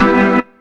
B3 EMIN 2.wav